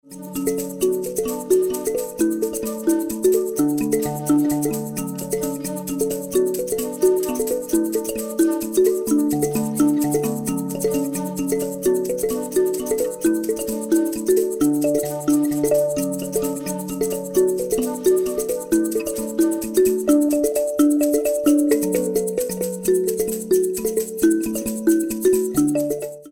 Kalimba & other instruments